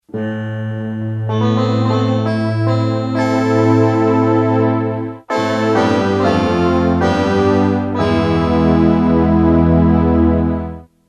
pads, a pair of piddly pipe